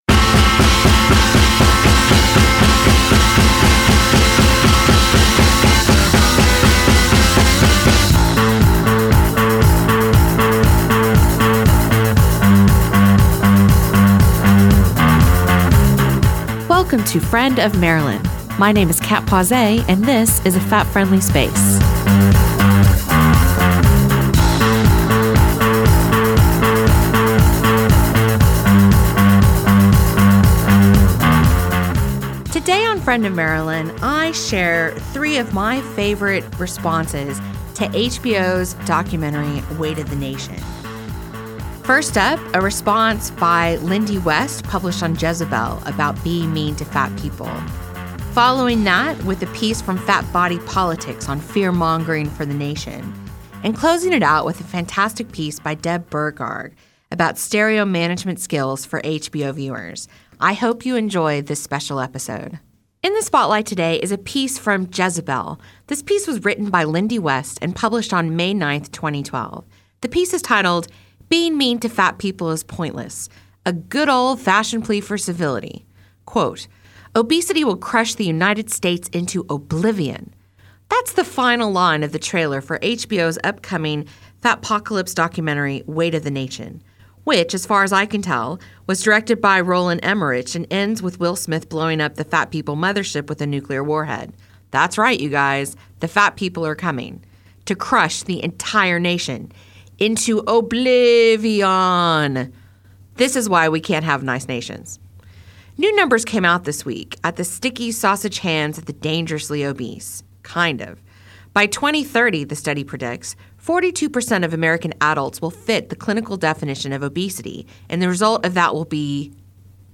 Friend of Marilyn was listened to around the world on multiple platforms and was broadcast locally by Manawatū People’s Radio online and on 999AM.
This episode has been edited from version originally broadcast to remove music by Gossip as rights and licences were not obtained by Manawatū Heritage.